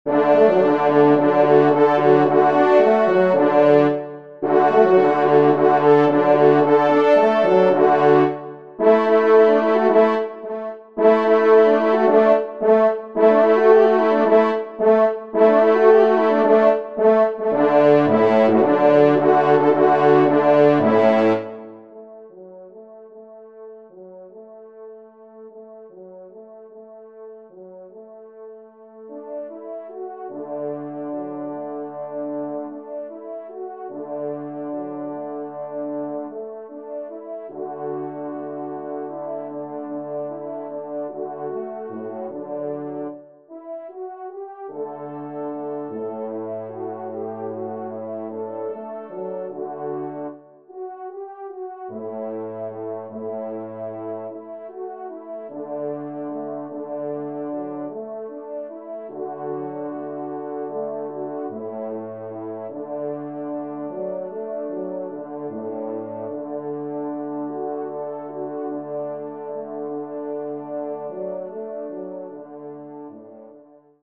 4e Trompe